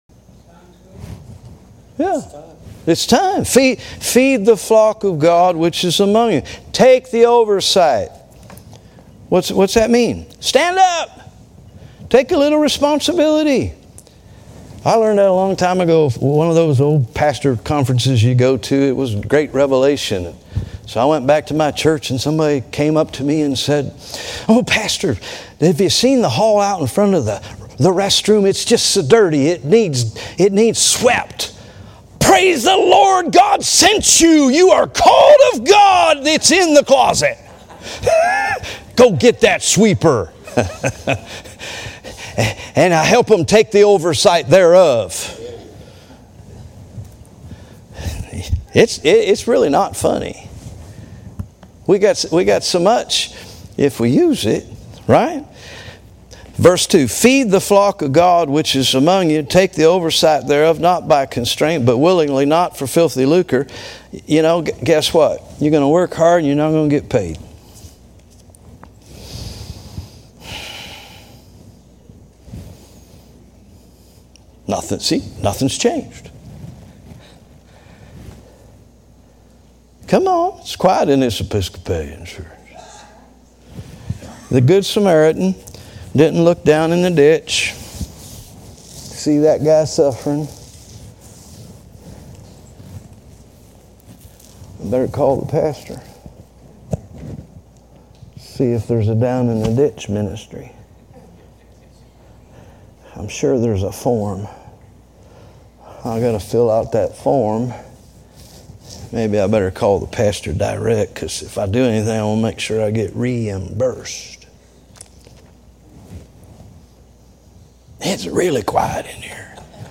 Preaching Service